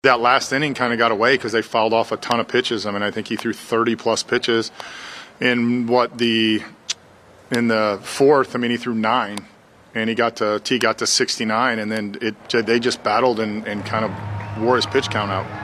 Shelton says Mitch Keller threw well, but the Guardians wore him down.